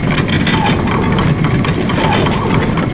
hugewheel.mp3